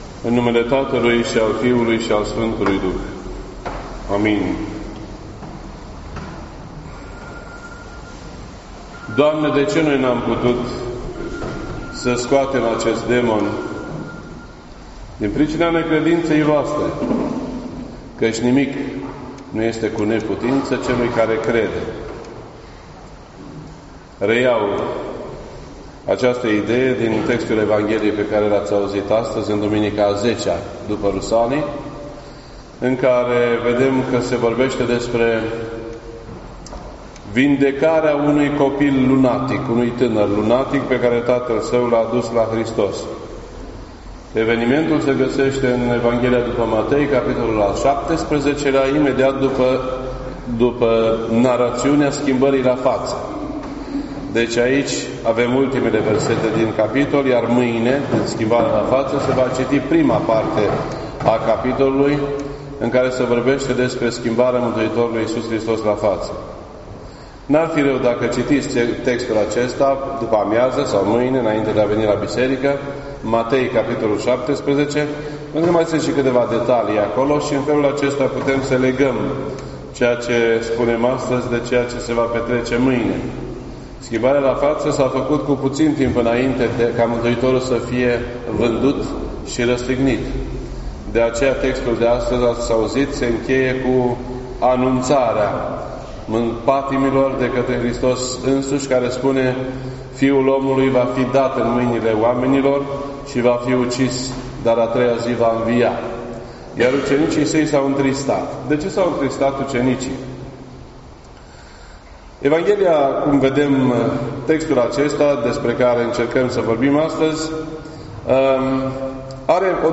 Predici ortodoxe in format audio